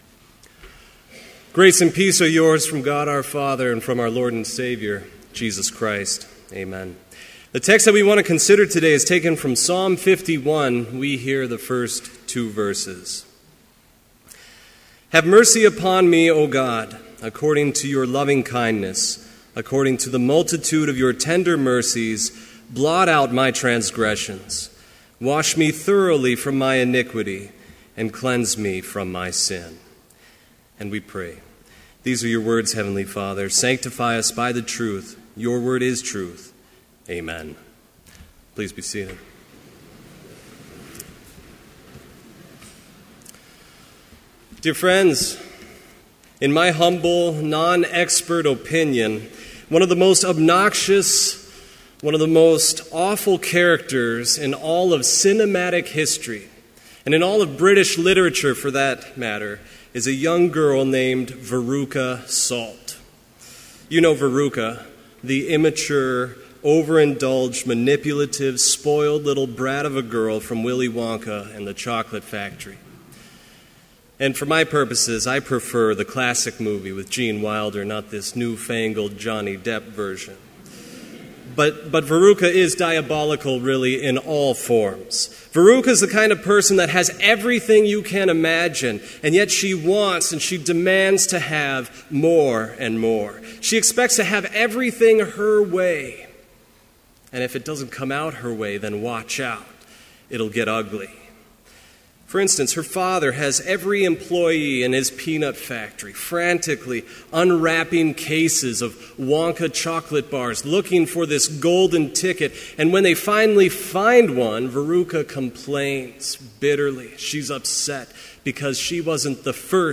Complete service audio for Chapel - September 2, 2014
Complete Service